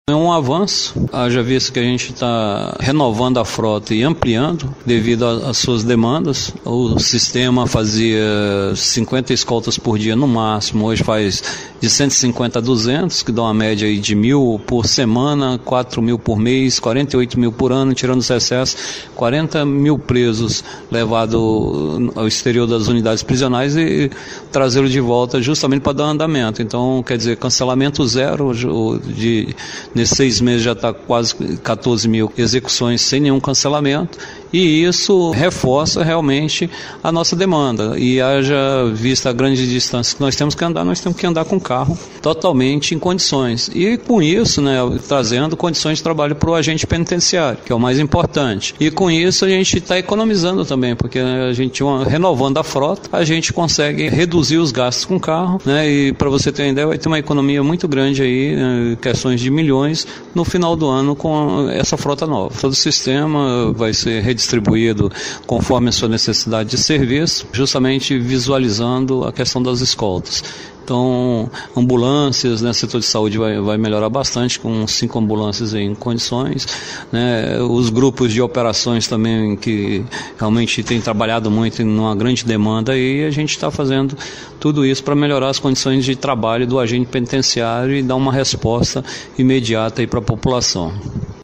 O secretário da SAP, Mauro Albuquerque, explica a importância da nova frota de veículos para o sistema prisional e para a melhoria das condições de trabalho dos agentes penitenciários.